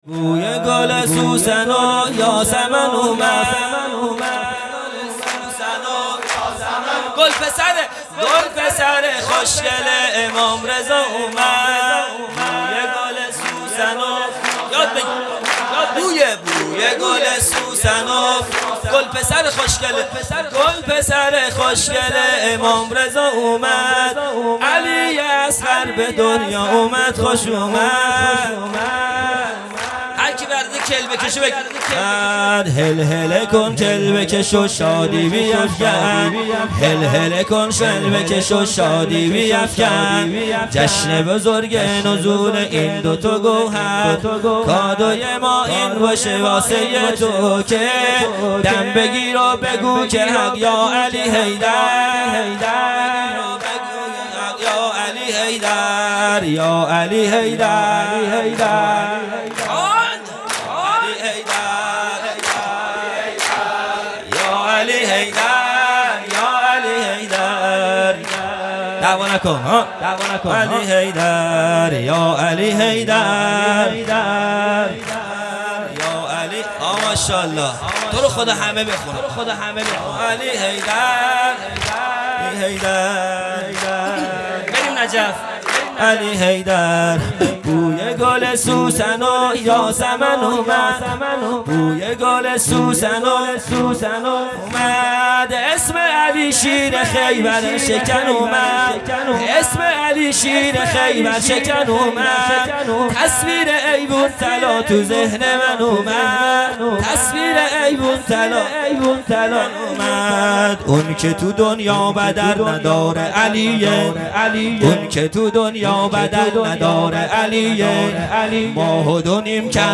خیمه گاه - هیئت بچه های فاطمه (س) - سرود | بوی گل سوسن و یاسمن اومد | 21 بهمن 1400
جلسۀ هفتگی